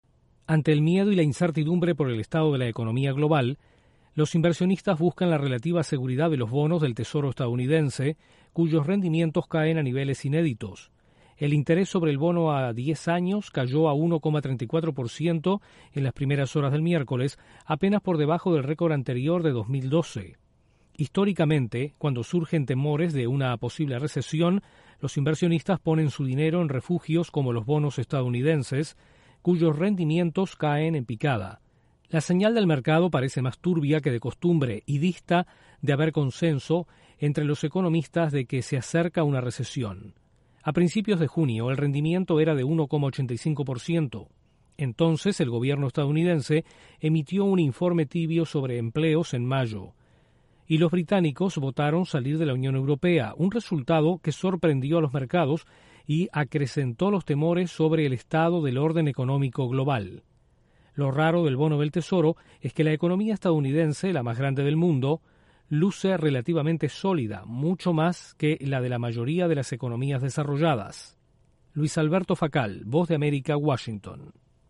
El rendimiento de los bonos del Tesoro de EE.UU. cae a niveles inéditos. Desde la Voz de América en Washington